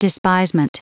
Prononciation du mot despisement en anglais (fichier audio)
Prononciation du mot : despisement